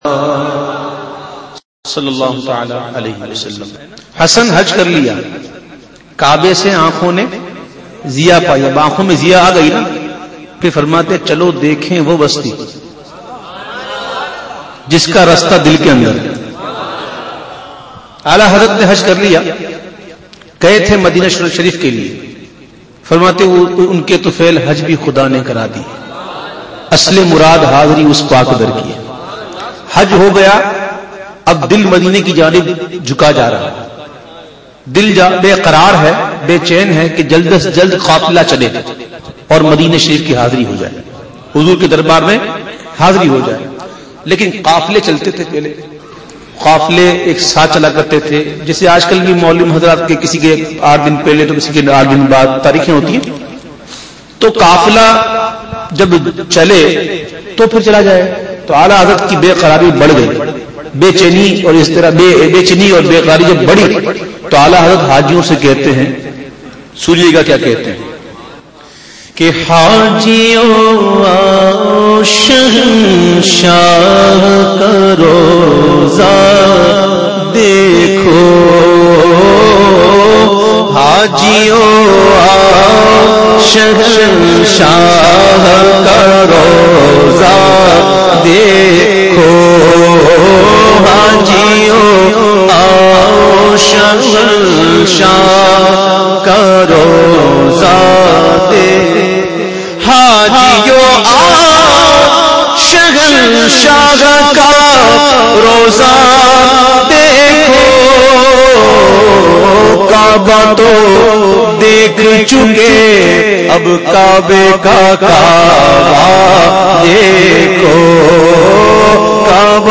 Exclusive Mehfil-e-Naat
in Karachi